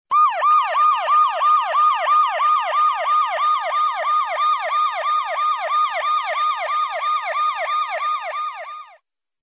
» Сирена скорой Размер: 39 кб